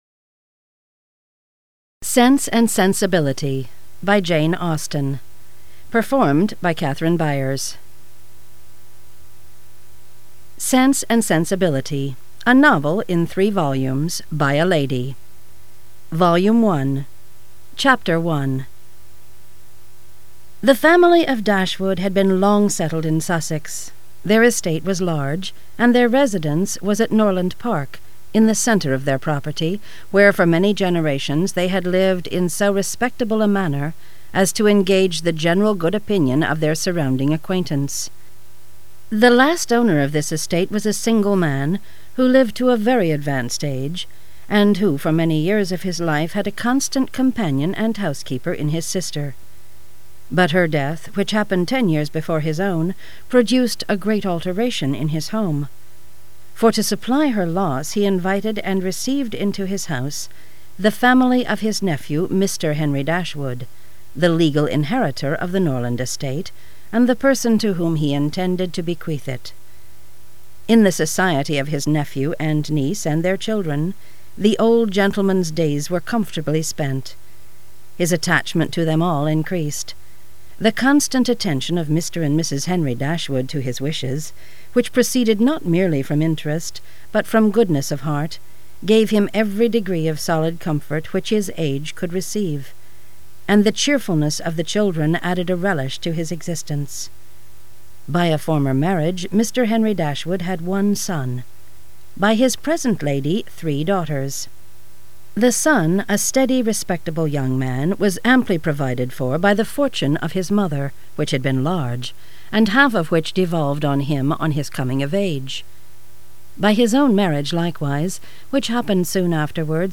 Sense & Sensibility by Jane Austen, unabridged audiobook mp3 d/l